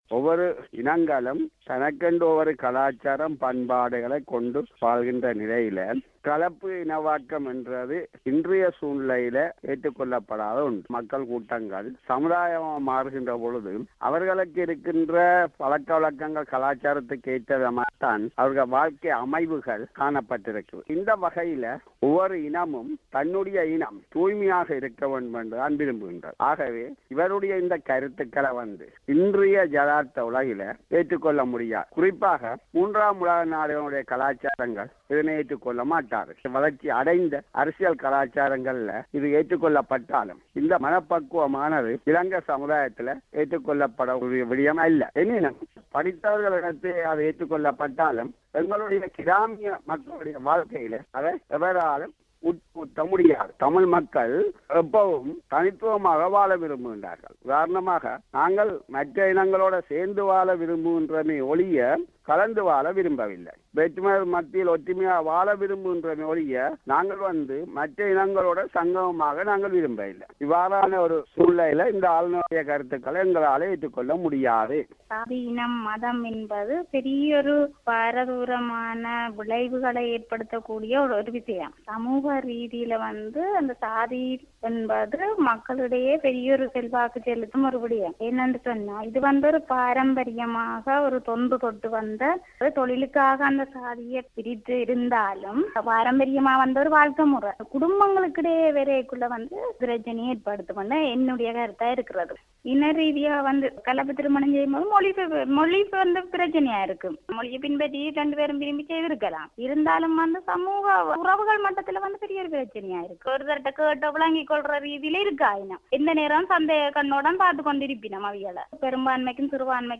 தேசிய அளவில் அனைத்து சமூக சயமங்களில் உள்ள மக்கள் கலப்புத் திருமணம் செய்ய வேண்டும், அப்படிச் செய்யும்போது கூடுதல் புரிதல் ஏற்பட்டு இணக்கப்பாட்டுக்கு வழி வகுக்கும் என அவர் தெரிவித்த கருத்தே விவாதப் பொருளாகியுள்ளது. இது குறித்து வடக்கு இலங்கையிலுள்ள சிலரது கருத்துக்கள்